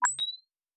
pgs/Assets/Audio/Sci-Fi Sounds/Interface/Data 08.wav at master